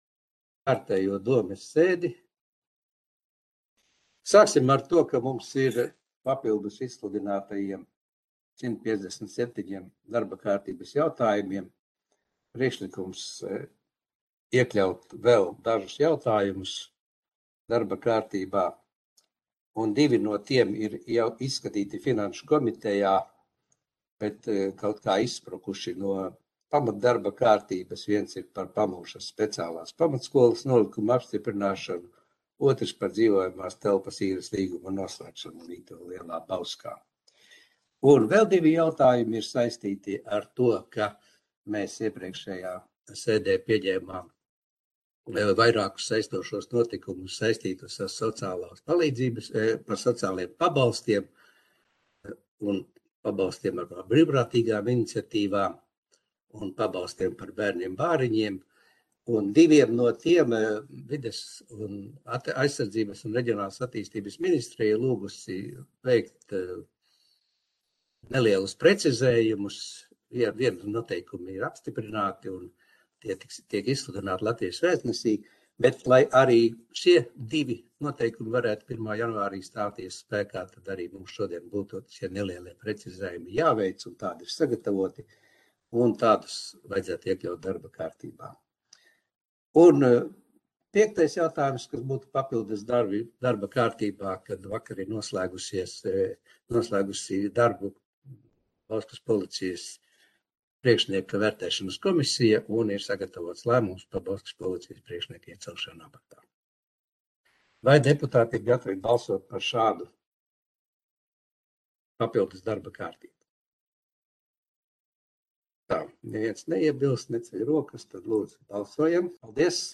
Audioieraksts - 2021.gada 23.decembra domes sēde